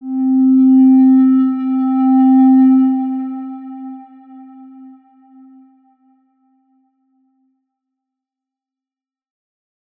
X_Windwistle-C3-mf.wav